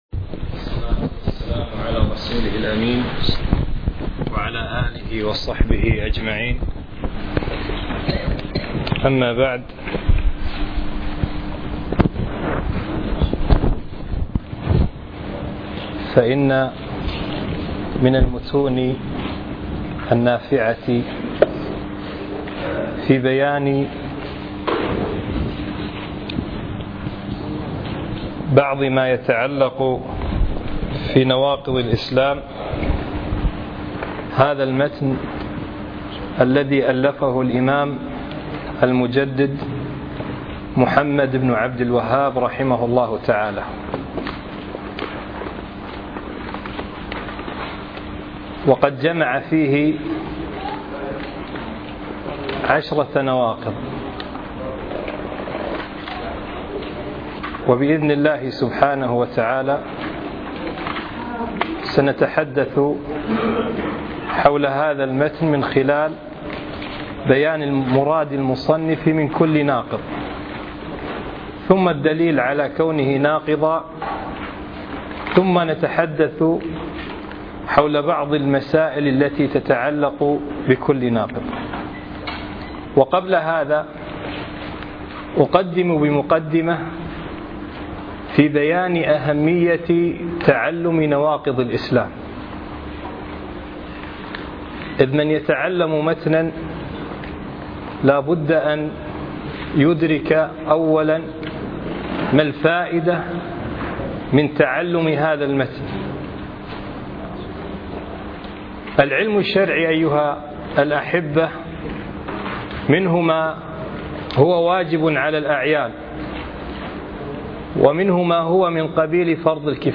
أقيمت الدورة في مسجد عبدالله الحمادي بمنطقة المهبولة من 6 1 إلى 8 1 2015